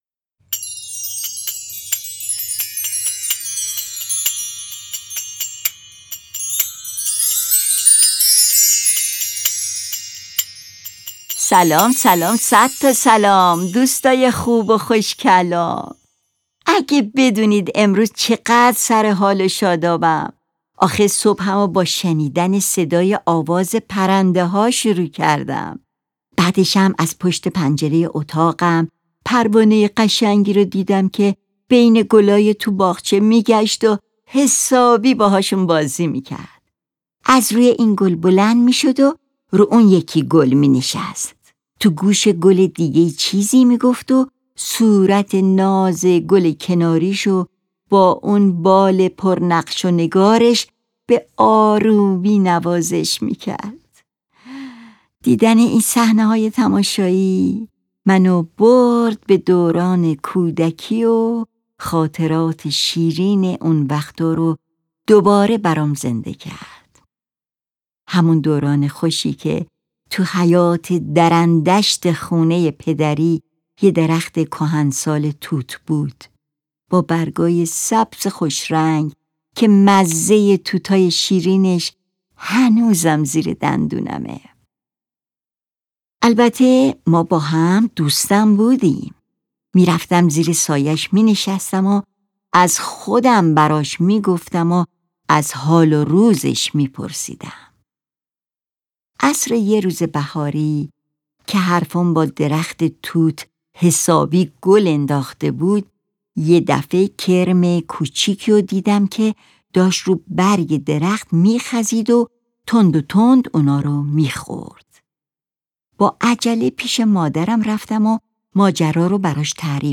لالایی
نغمه‌های آرام و دلنشینی که با صدای جادویی ساز‌هایی همچون: پیانو، ویولون، باسون، گیتار، فلوت، بلز و سمپل‌های گوش نواز جعبه موسیقی، که بسیار هم خیال انگیزند؛ شنیدنی‌تر می‌شوند؛ تا علاوه بر نوزادان و کودکان، والدین شان نیز همراه آنها، لذت یک خواب شیرین را تجربه کنند.